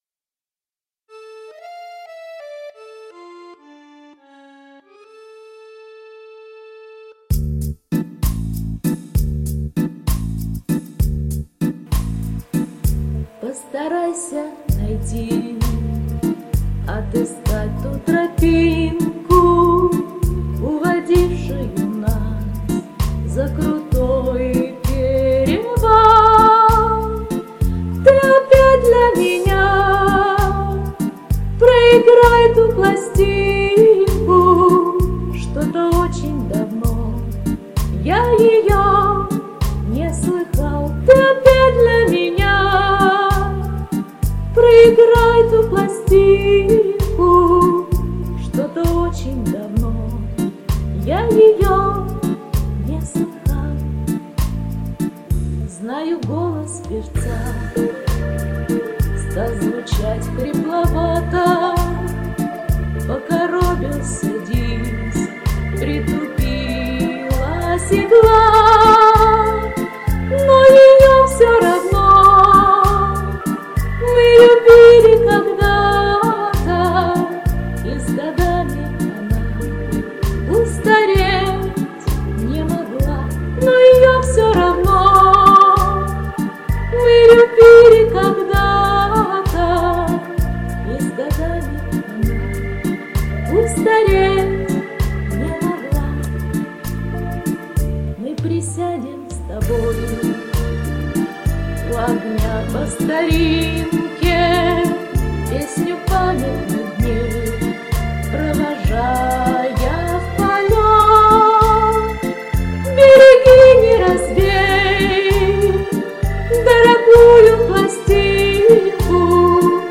Голос очень красивый.